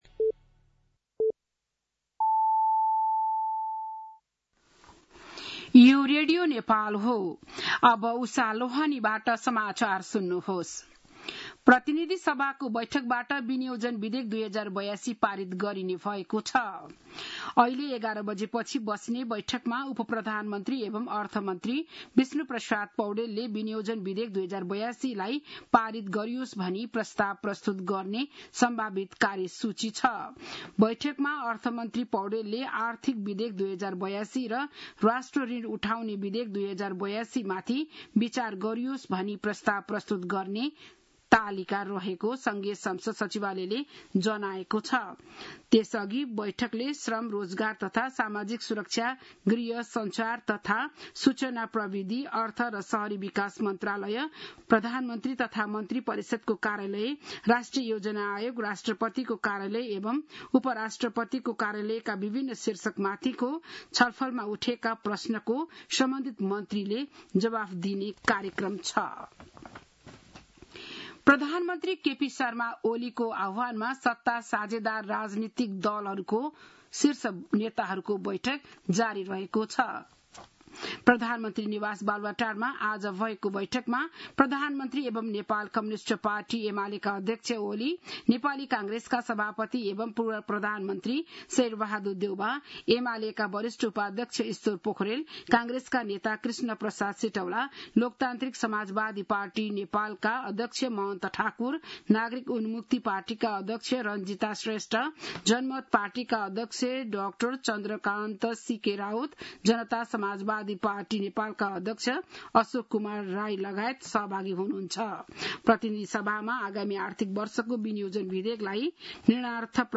बिहान ११ बजेको नेपाली समाचार : १० असार , २०८२
11am-News-10.mp3